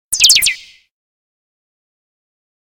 Птичка